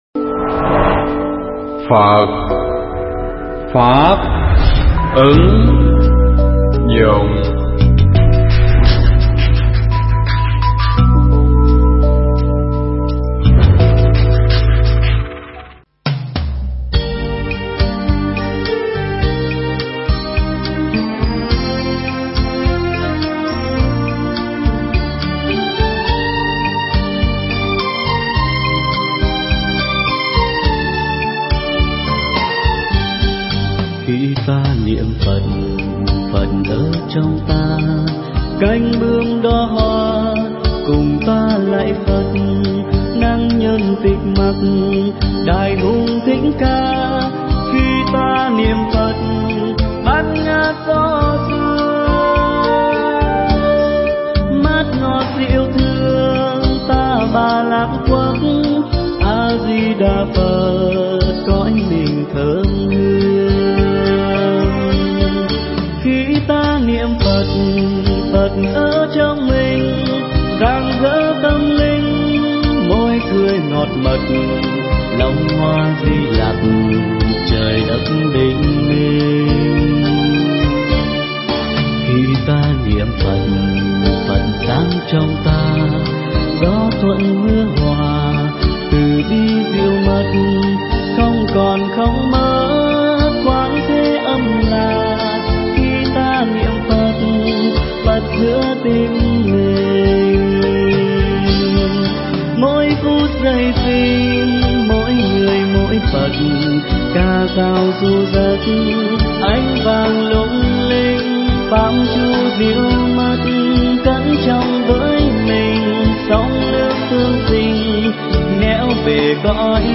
Nghe Mp3 thuyết pháp Niềm Chánh Tín Trong Đạo Phật
Mp3 pháp thoại Niềm Chánh Tín Trong Đạo Phật